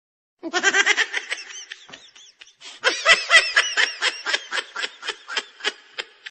Squirrel Laugh Comedy Sound Effect Free Download
Squirrel Laugh Comedy